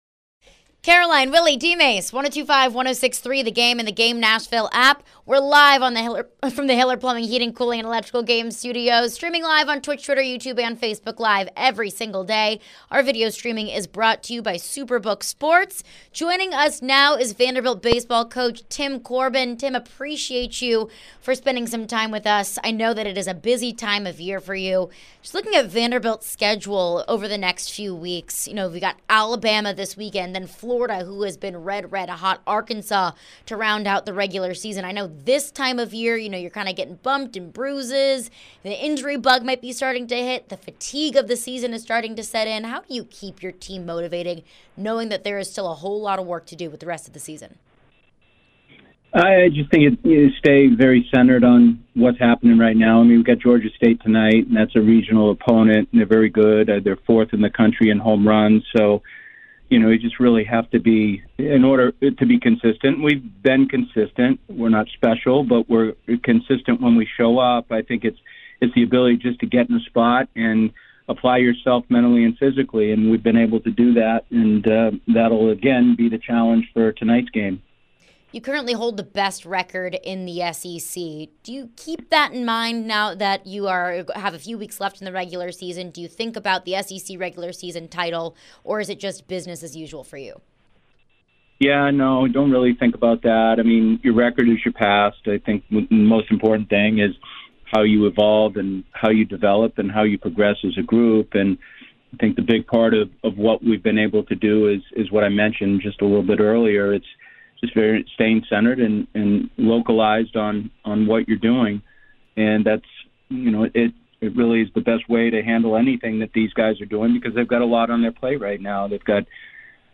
Tim Corbin Interview (5-2-23)